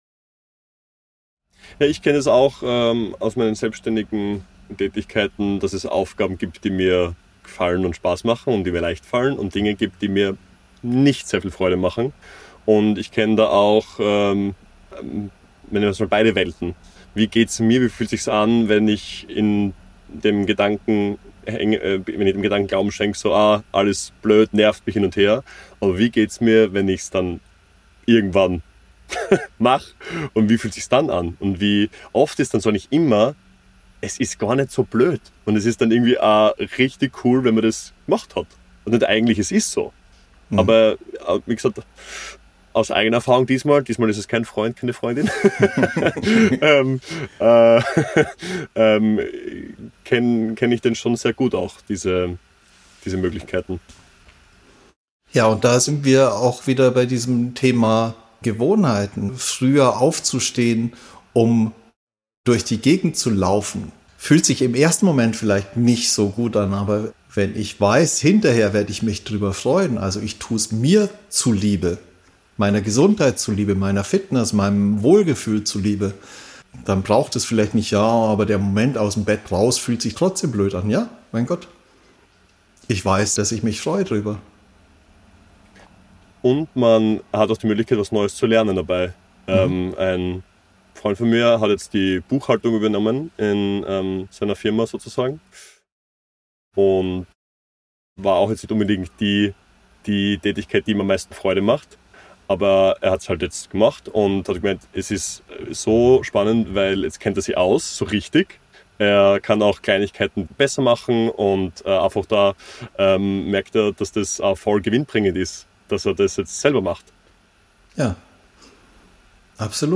Im letzten Teil des Gesprächs geht es um das Entstehen neuer Gewohnheiten – nicht durch Druck oder Überwindung, sondern aus einer Haltung von Zuwendung und Klarheit.